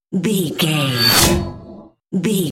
Whoosh electronic fast
Sound Effects
Atonal
Fast
futuristic
high tech
whoosh